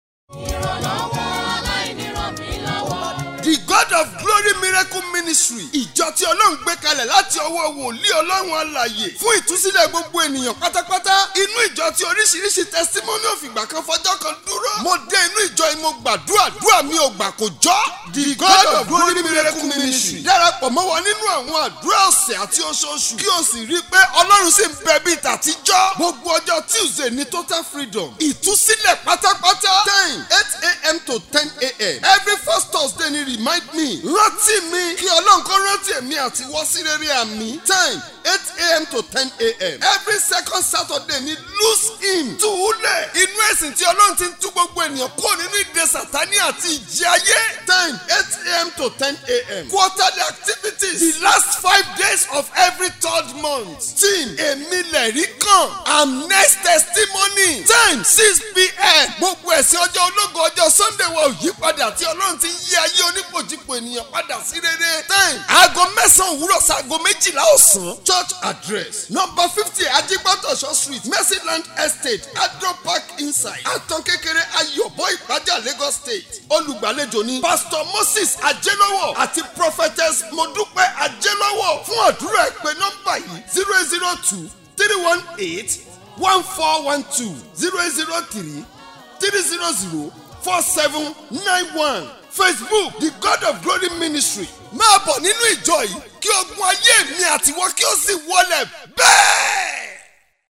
Praise worship Aug 27, 2025